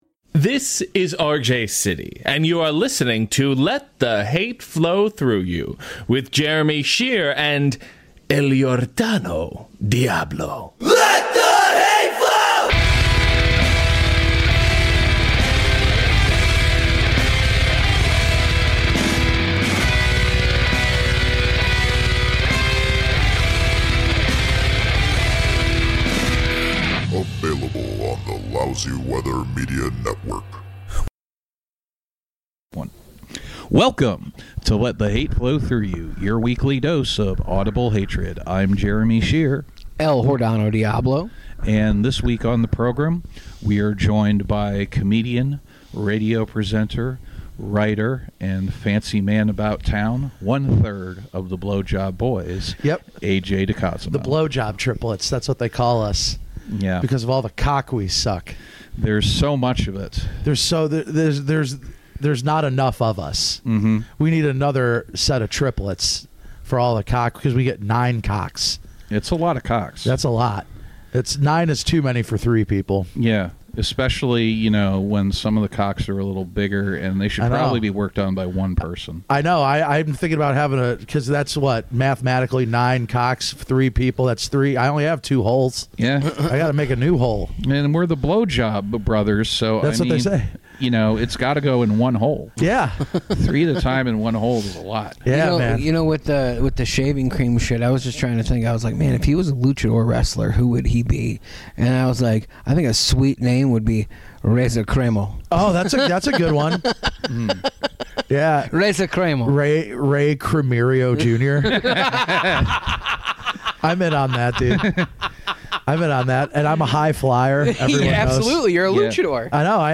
Two portly gentlemen try to contain their excitement as they discuss the proliferation of smash burgers across the world.
We also fight about the Epstein Files. In the middle of the episode, the camera cut out and we had to restart, so nothing out of bounds was edited out, that's all it was.